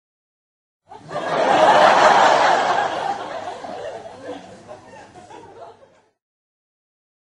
Звуки смеха
Звук смеха как в ситкоме